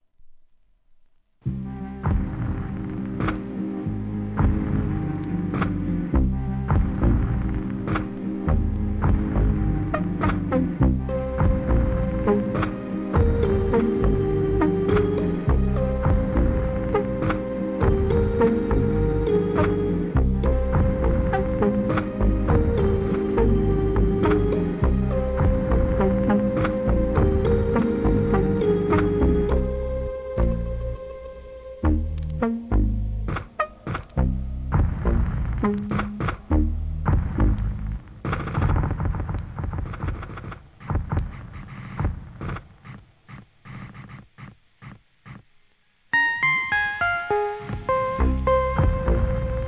オーガニックなAmbient ブレイクビーツ!